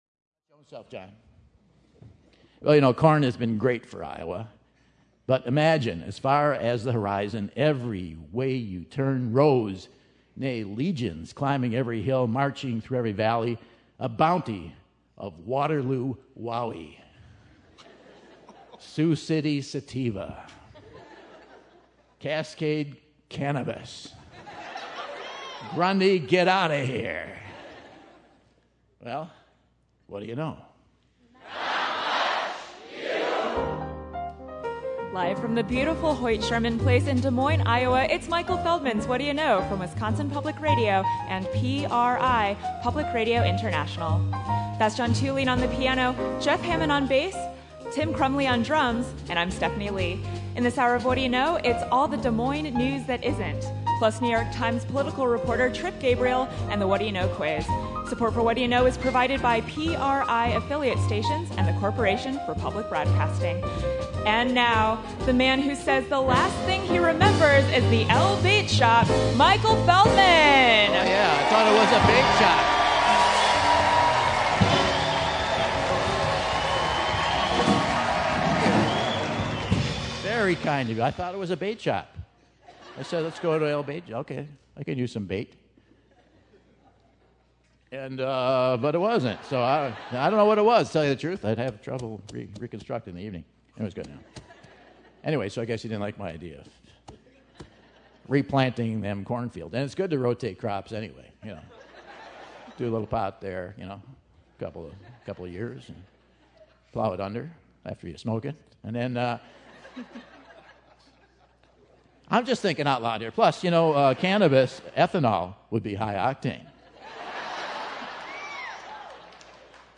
September 12, 2015 - Des Moines, IA - Hoyt Sherman Place | Whad'ya Know?